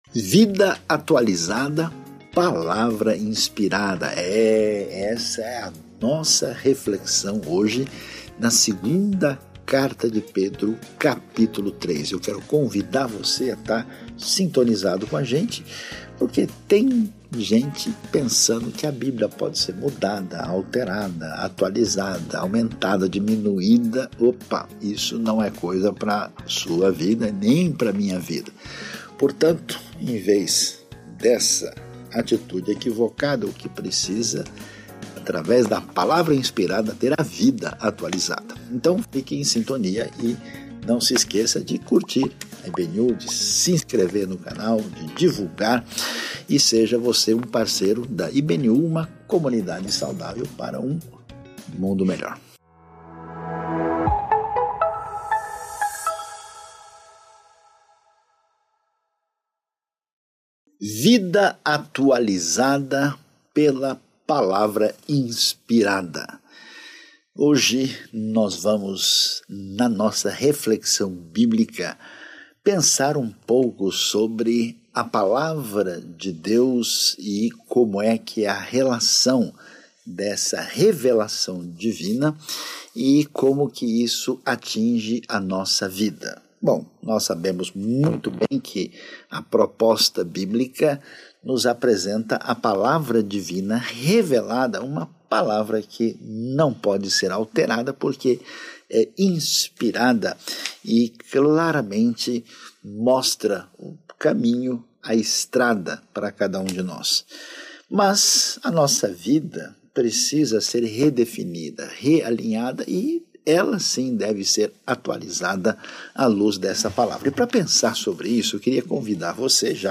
na Igreja Batista Nações Unidas